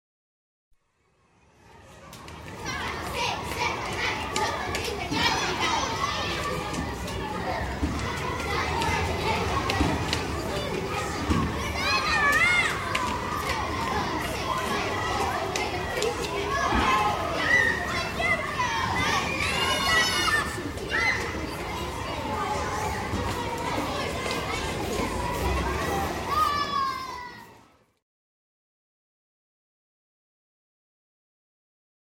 playground.mp3